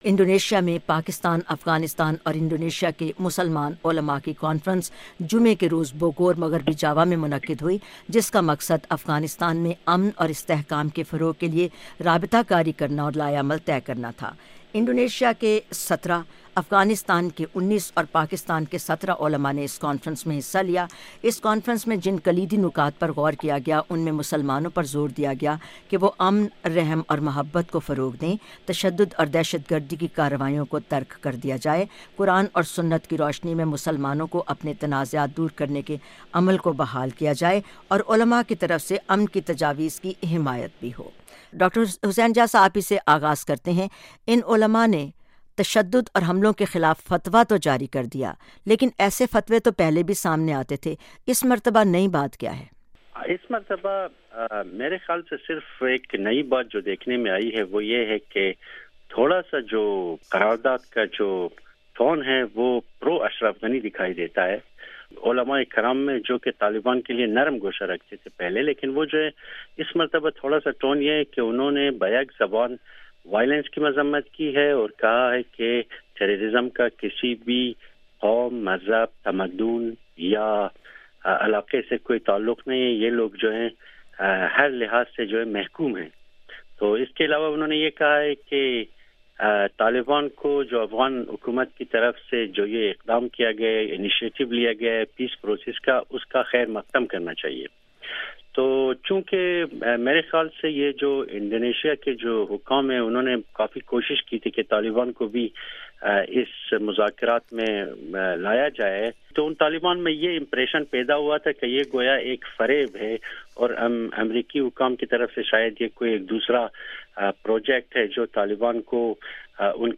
Jahan Rang: Analysts dilate on Islamic religious scholars' edict against extremism